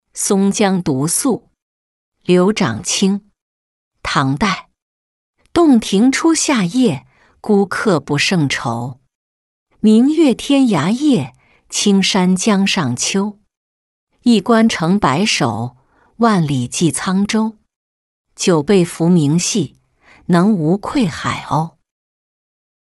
松江独宿-音频朗读